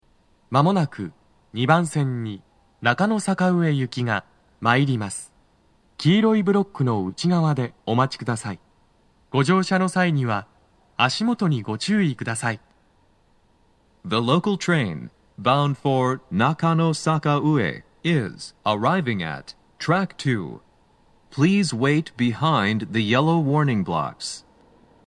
スピーカー種類 TOA天井型()
鳴動は、やや遅めです。
2番線 中野坂上・池袋方面 接近放送 【男声